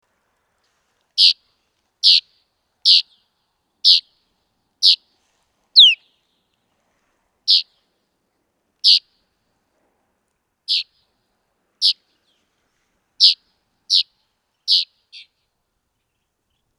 McKay’s Bunting